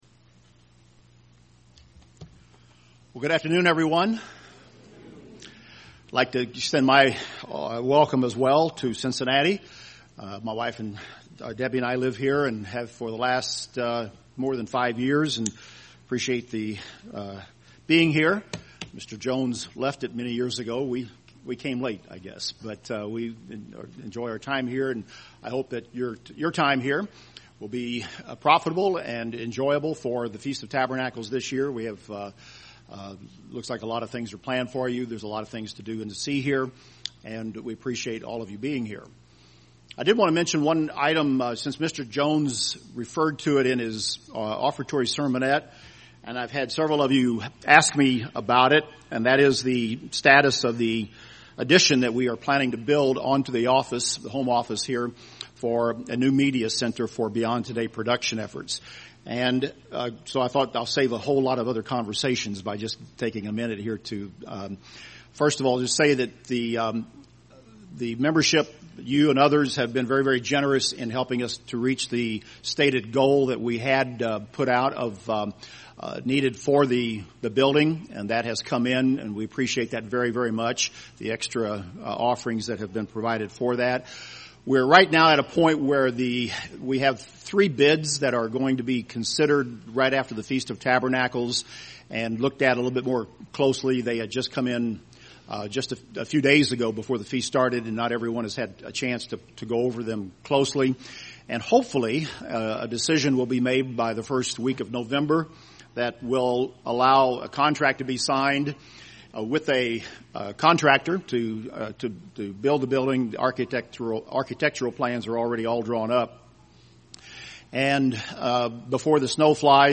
This sermon was given at the Cincinnati, Ohio 2017 Feast site.